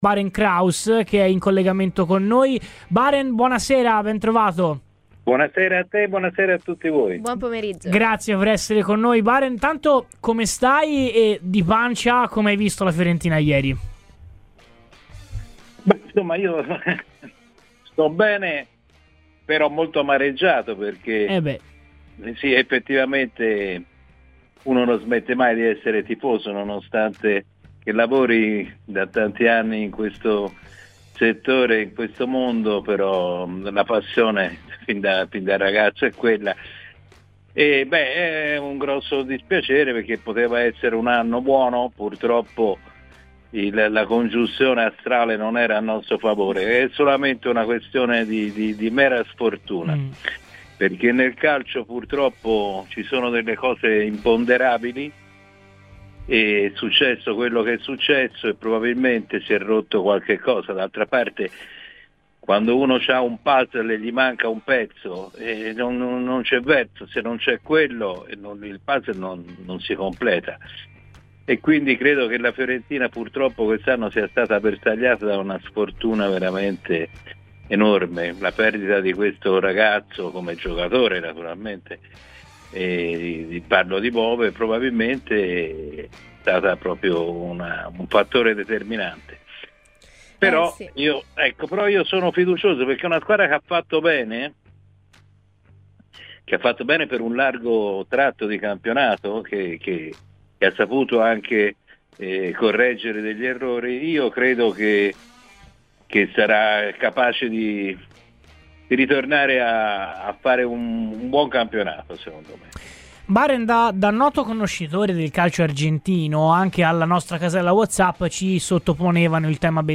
Radio FirenzeViola